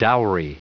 Prononciation du mot dowry en anglais (fichier audio)
Prononciation du mot : dowry